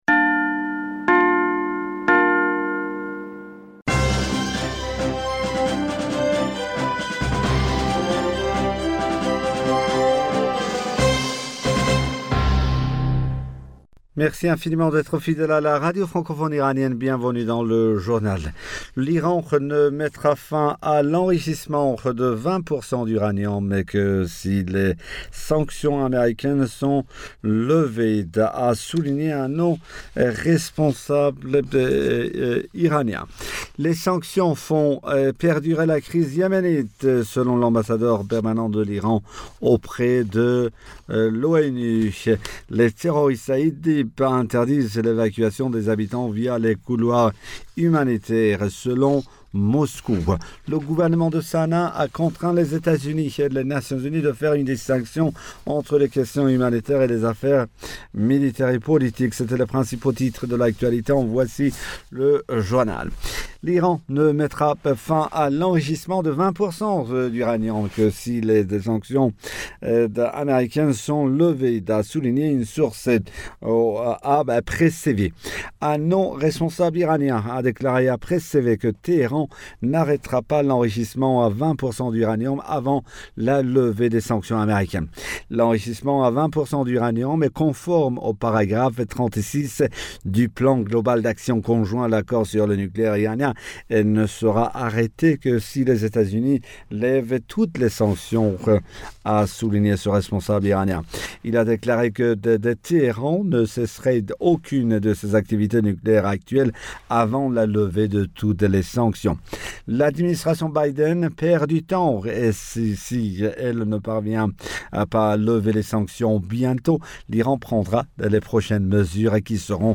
Bulletin d'informationd du 30 Mars 2021